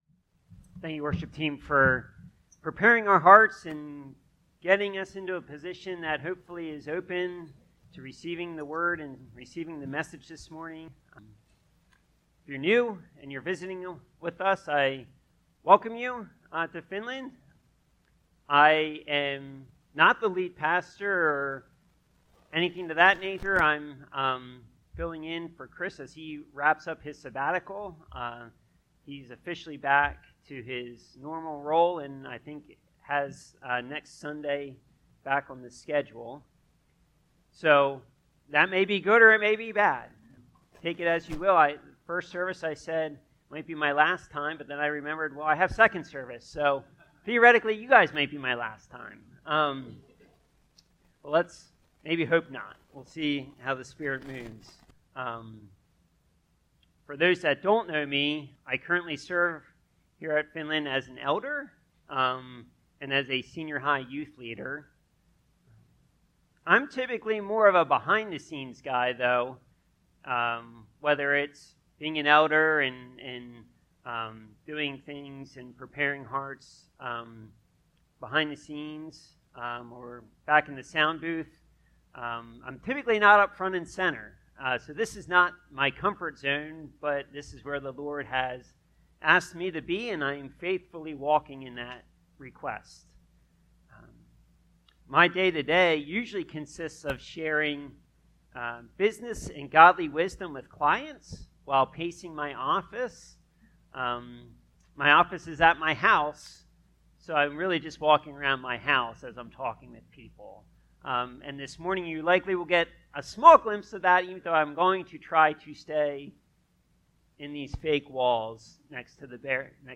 Finland Mennonite Church - The Way of the Kingdom - Sermon Series